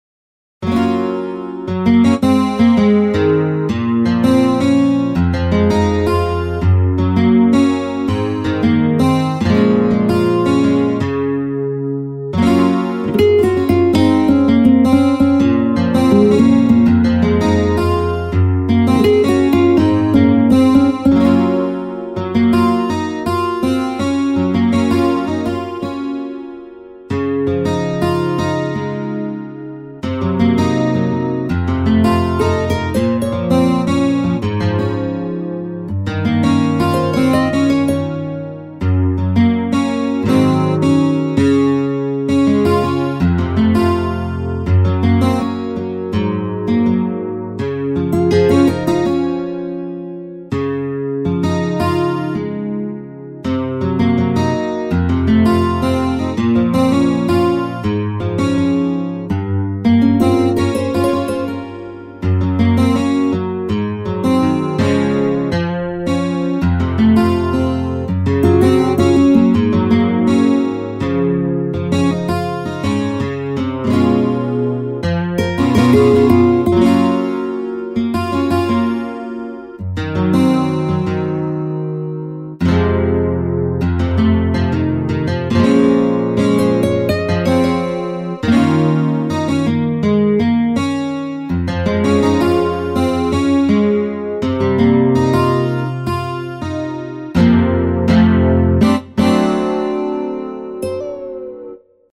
HALion6 : A.Guitar
Campfire Guitar